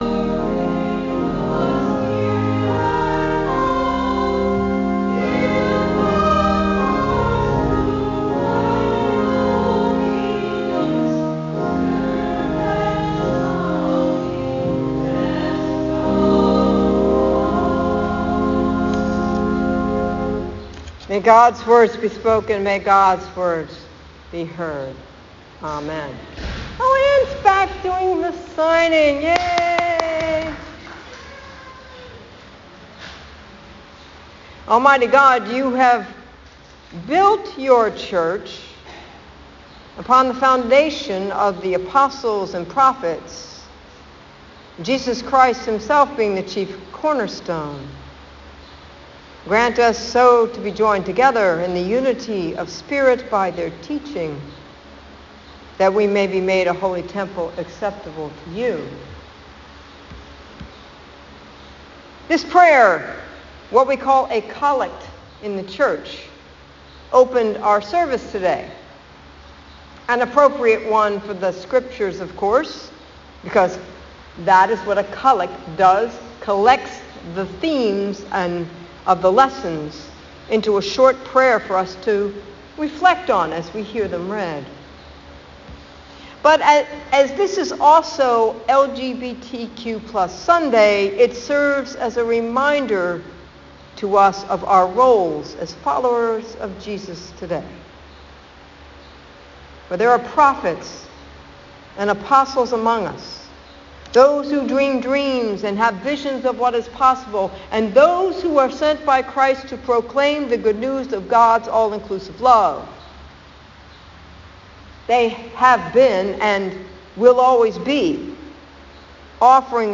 This prayer, what we call a collect in the church, opened our service today.